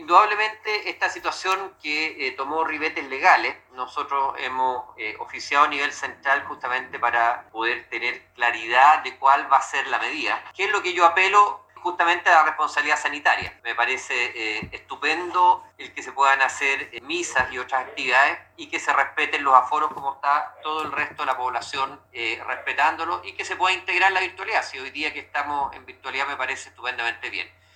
Al respecto, el Seremi de Salud de la región de Los Lagos, Alejandro Caroca, indicó que oficiaron al nivel central, con el fin de saber cómo se actuará frente a este fallo.